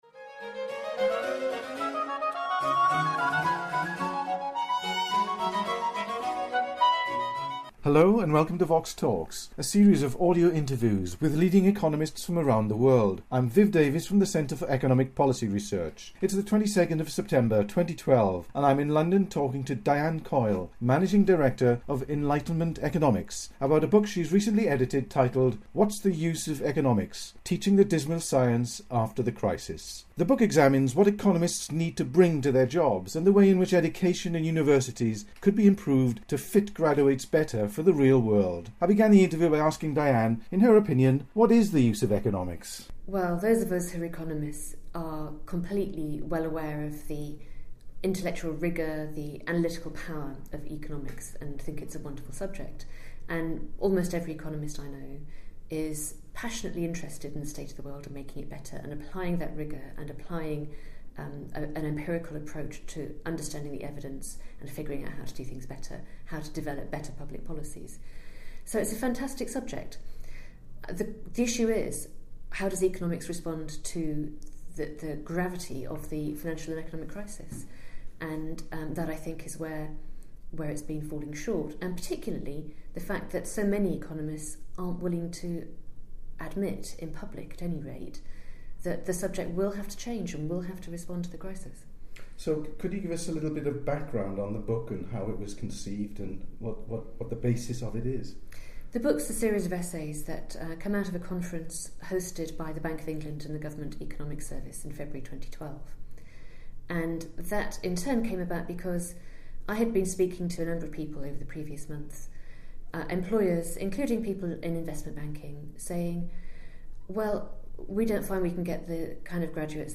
They discuss what economists need to bring to their jobs and the way in which education in universities could be improved to fit economic graduates better for the real world. The interview was recorded in London on 22 September 2012.